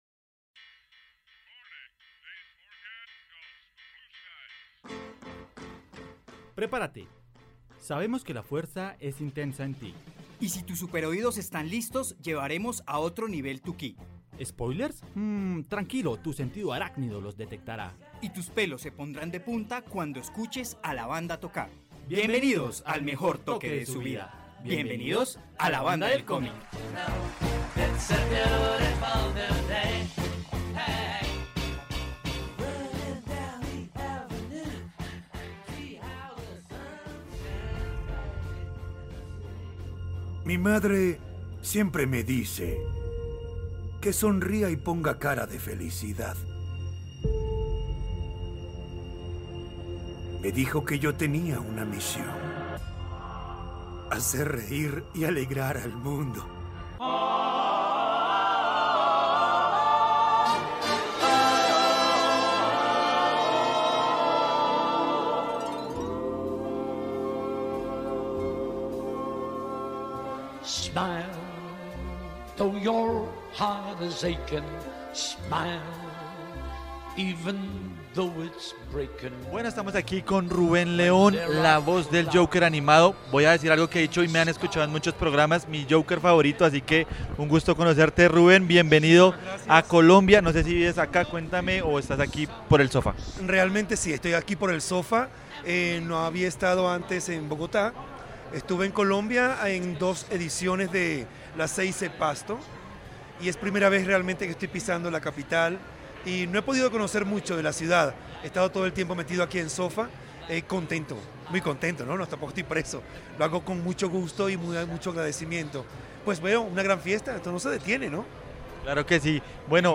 En pleno SOFA